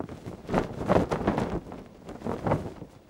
cloth_sail3.L.wav